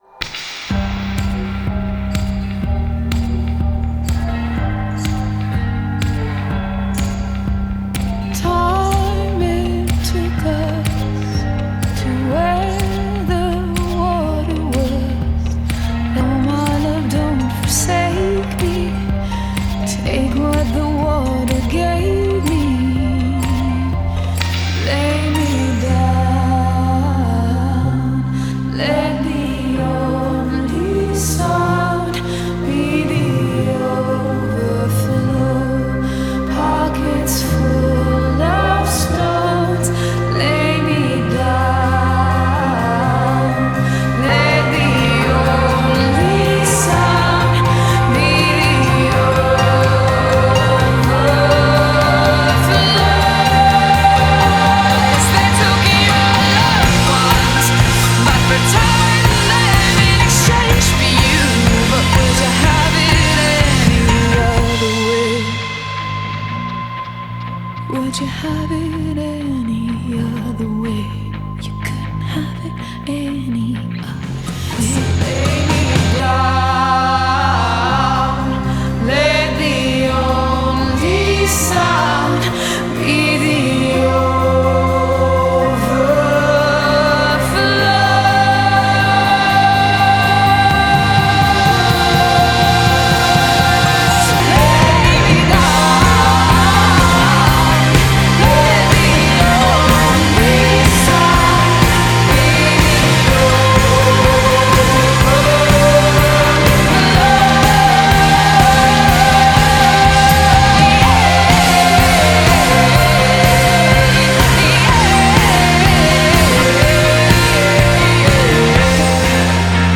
BPM124
Audio QualityCut From Video